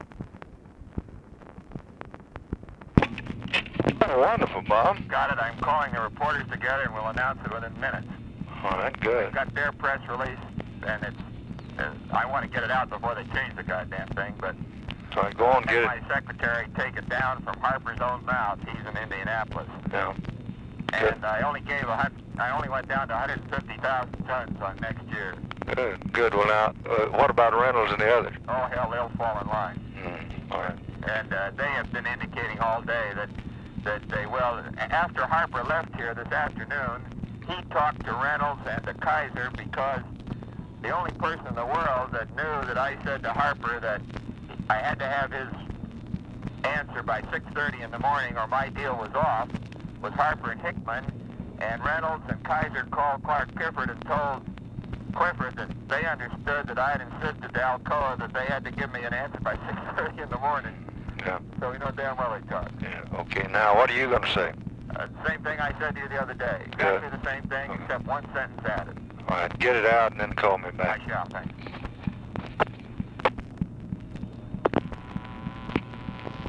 Conversation with ROBERT MCNAMARA, November 11, 1965
Secret White House Tapes